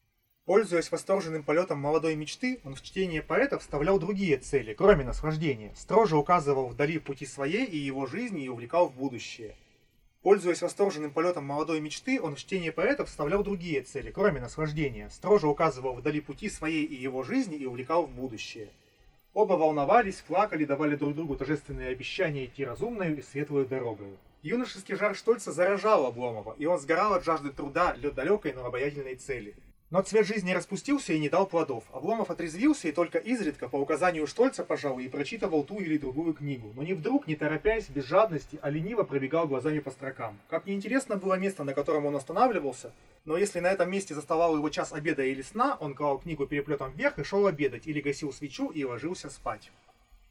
Пример записи на микрофон (стерео, говорящий в 70 см)
Голос довольно заметно перемещается из канала в канал — стереоэффект создается. Возможно, он не так ярко выражен, как при применении полноценных бинауральных микрофонов, но все-таки он есть.